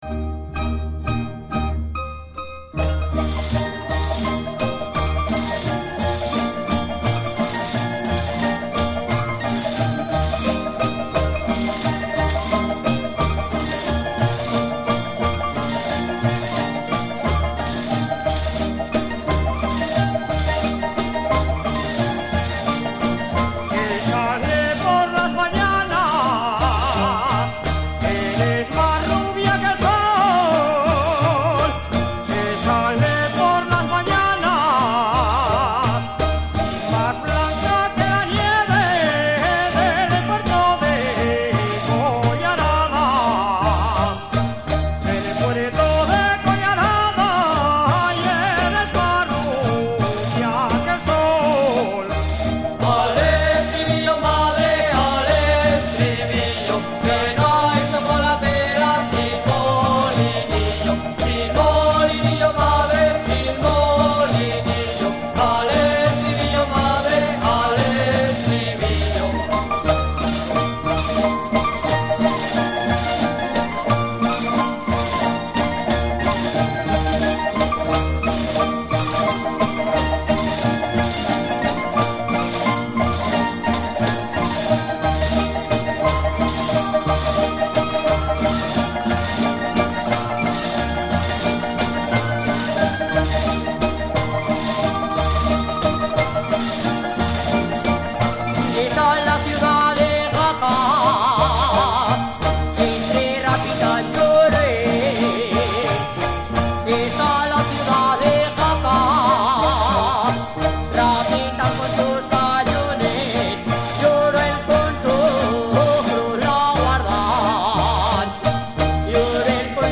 ronda_altoaragonesa.mp3